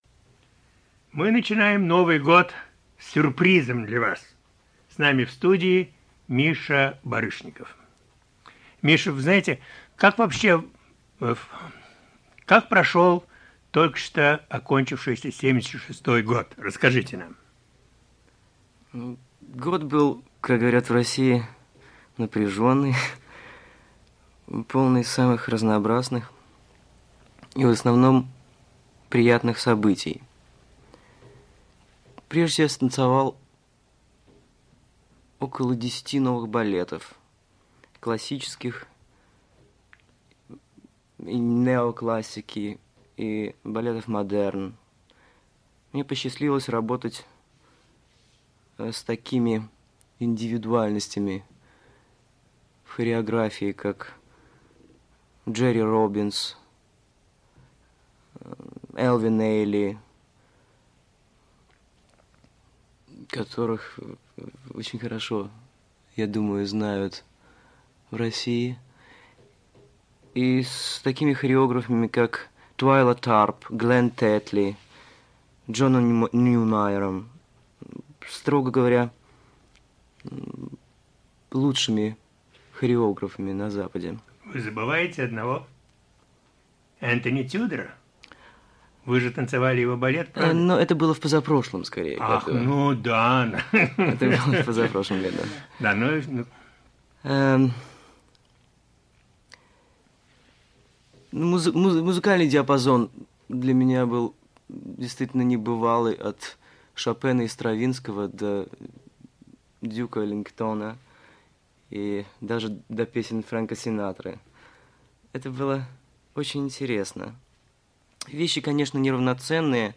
Михаил Барышников – интервью 1977
Из архива «Голоса Америки» 1977: легендарный танцовщик Михаил Барышников рассказывает о своем творчестве.